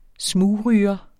Udtale [ ˈsmuːˌʁyːʌ ]